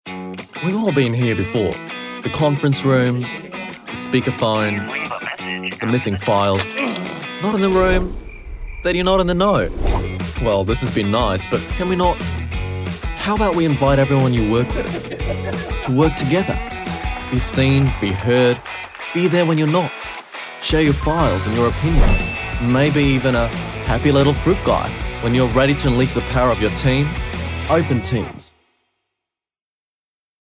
Commercial Reel (Australian)
Commercial, Cheeky, Cool, Fun
Australian